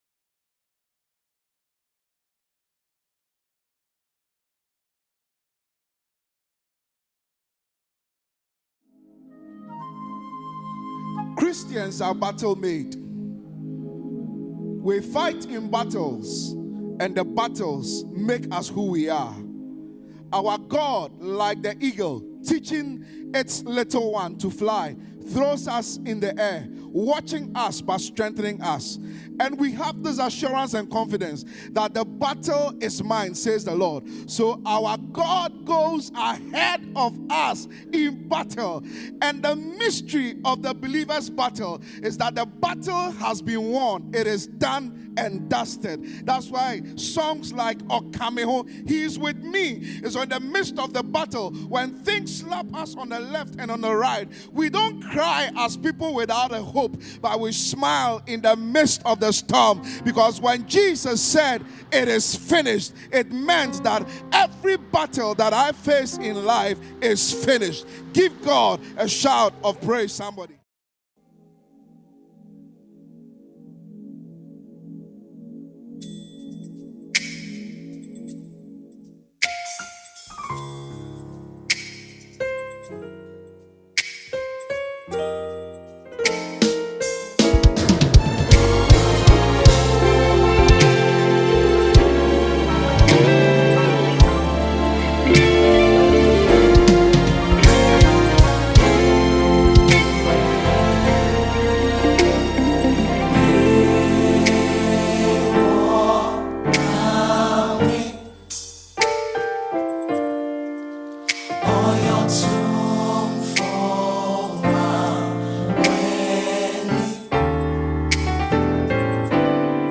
If you are looking for uplifting Christian music
Genre: Gospel